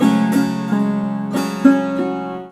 SAROD3    -L.wav